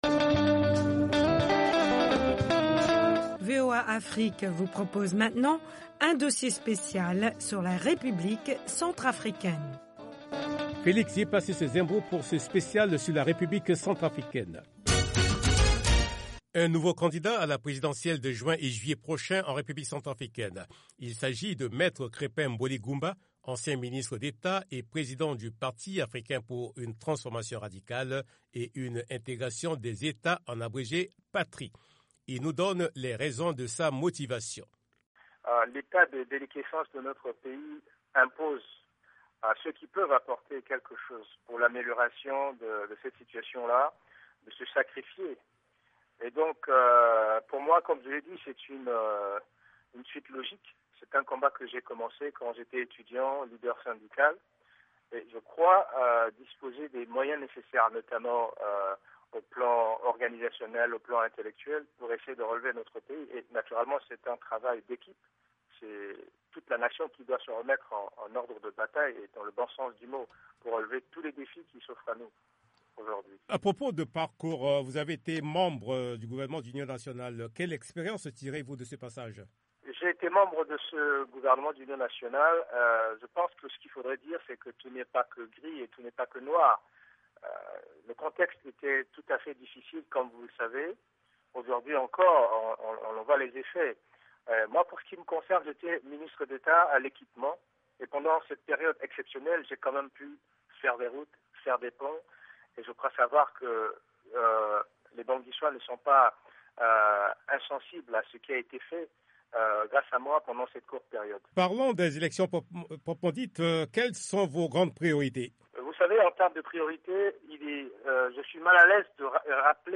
RCA: Reportage Special